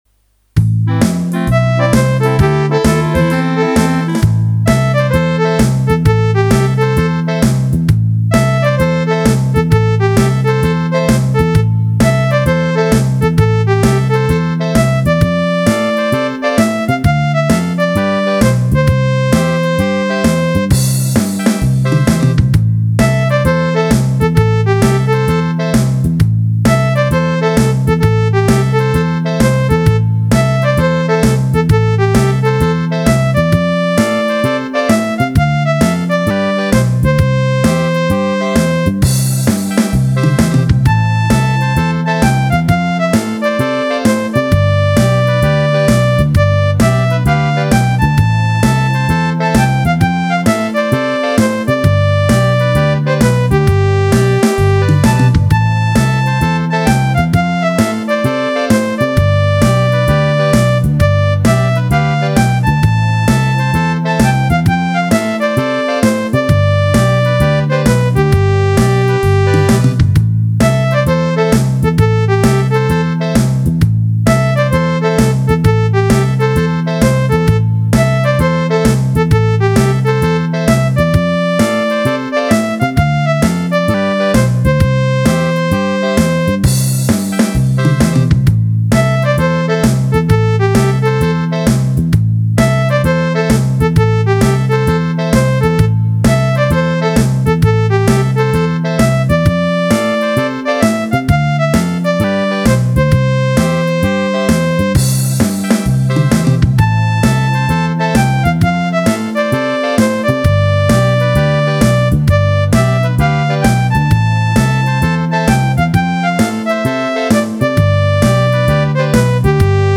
Jazz
088 Big Band Medium
Ik heb het bewust eenvoudig gehouden, maar precies daarin zit de charme: een toegankelijke, sfeervolle aanpak met een klassieke bigband-vibe.
Roland E X 10 Jazz 088 Big Band Medium Mp 3